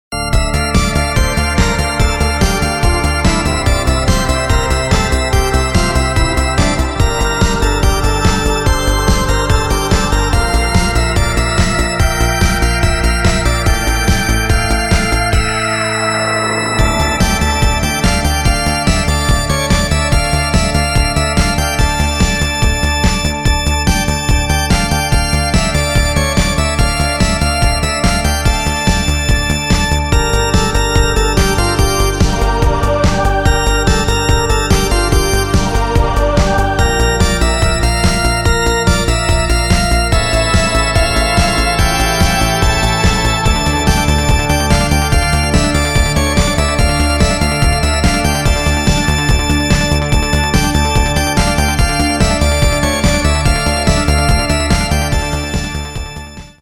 • Качество: 320, Stereo
инструментальные
труба
классика
электроника
психо-электронная музыка